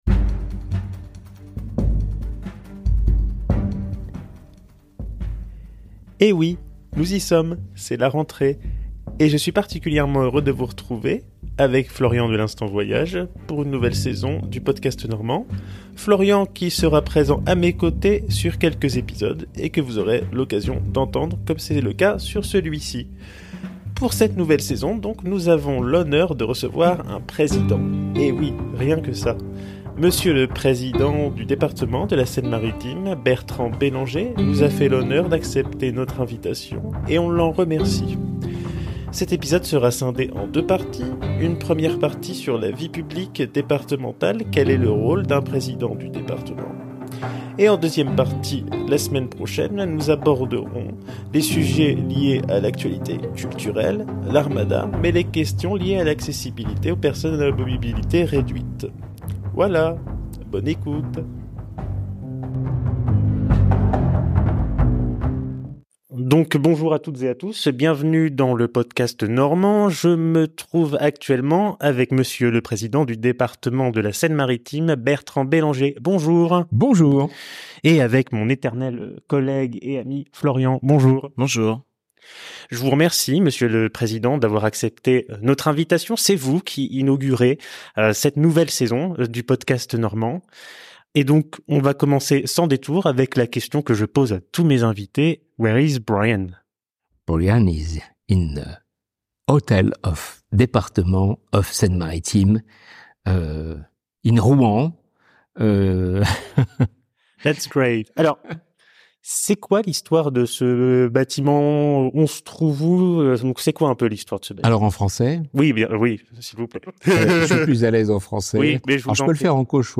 Handicap et politiques publiques en Seine-Maritime – Entretien avec Bertrand Bellanger
Monsieur le président du département de la Seine Maritime a accepté notre invitation et nous le remercions.